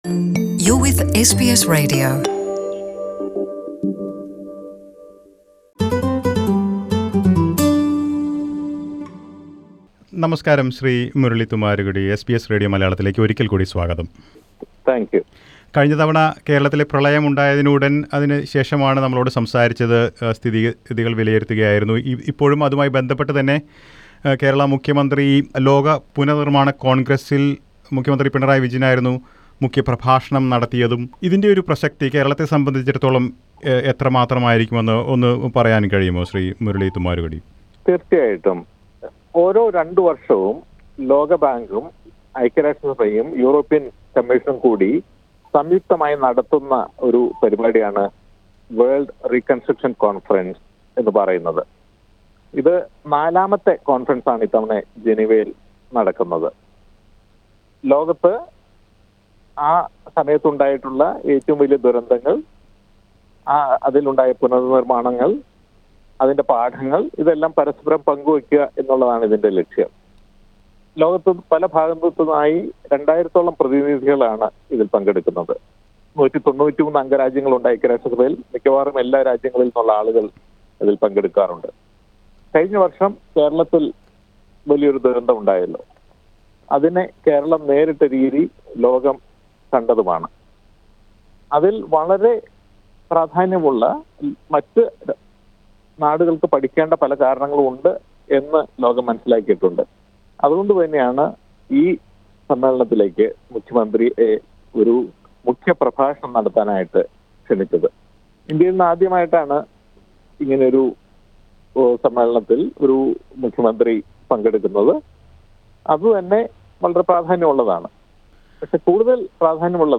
UN Disaster Management chief explains the relevance of Kerala Chief Minister's speech in Geneva